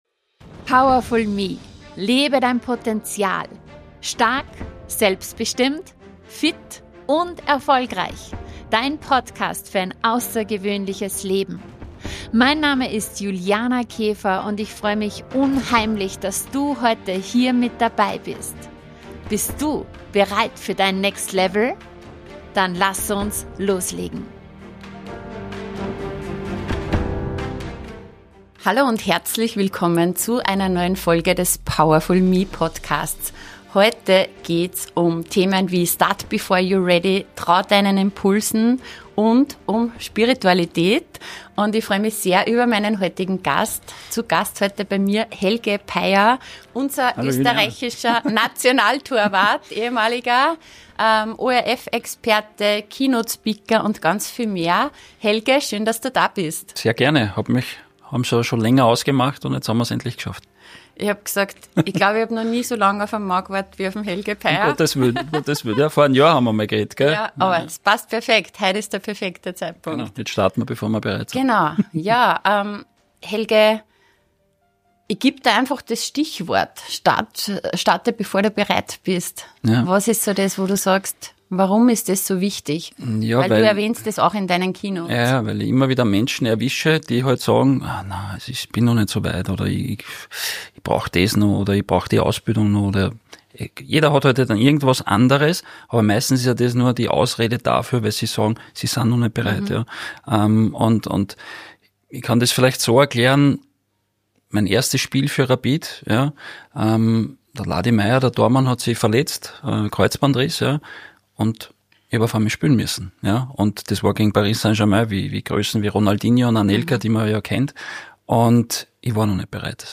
Trau deinen Impulsen: Warum der nächste Schritt nie „perfekt“ ist Ein sehr persönliches Gespräch